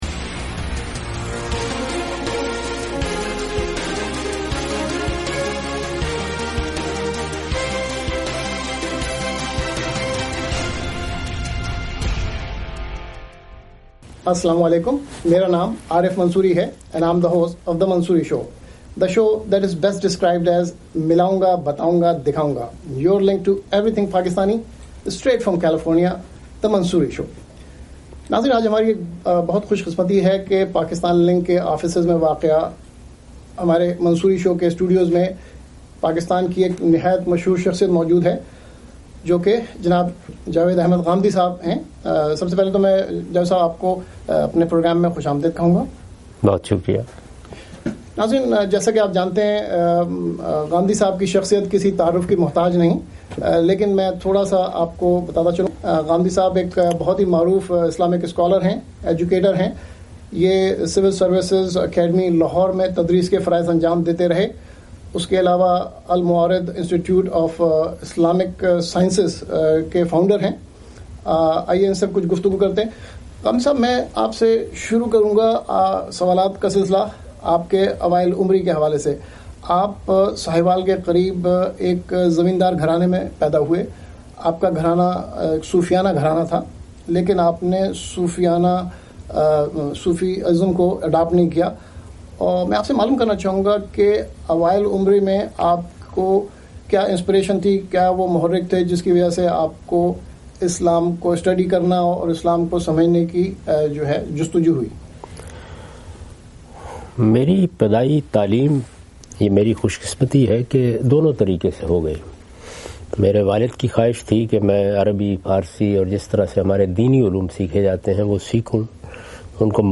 This Interview was Telecast on ARY Digital Chicago USA on February 23, 2018.